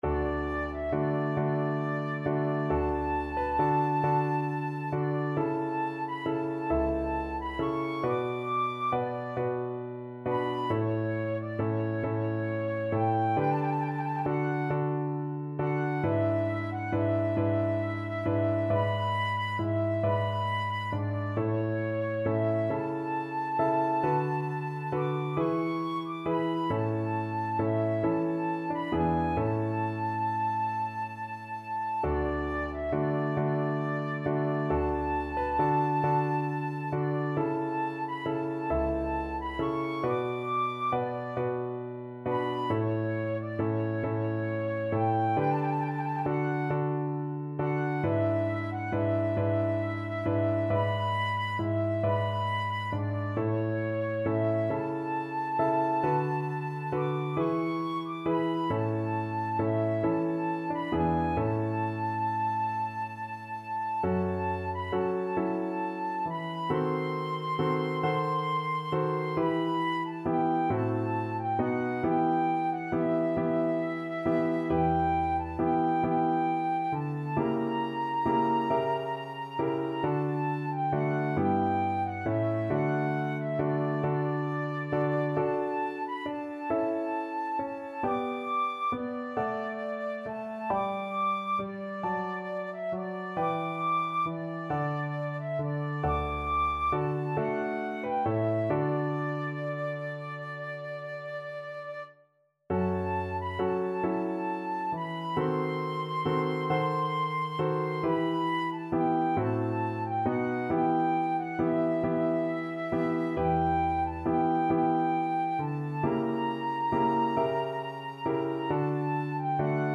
Flute
D major (Sounding Pitch) (View more D major Music for Flute )
12/8 (View more 12/8 Music)
II: Larghetto cantabile .=45
Classical (View more Classical Flute Music)